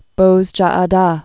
(bōzjä-ä-dä) or Formerly Ten·e·dos (tĕnĭ-dŏs, -ĭ-dōs, -ə-thôs)